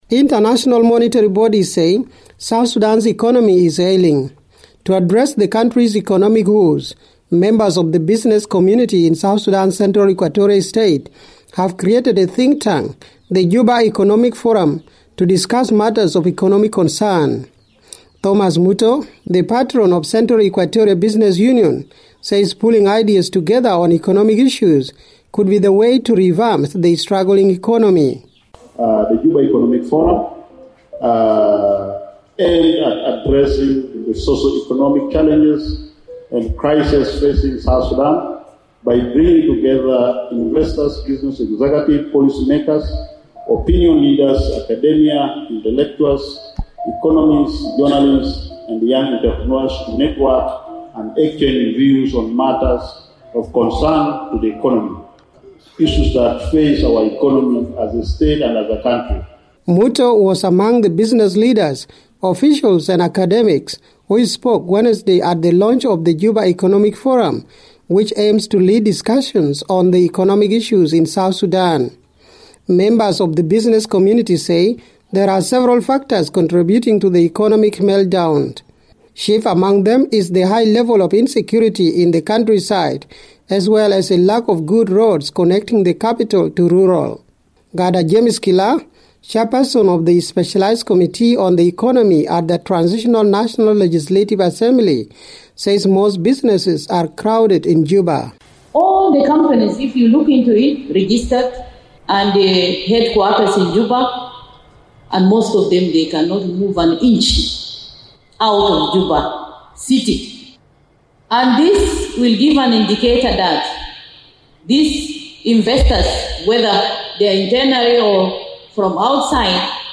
reports from Juba.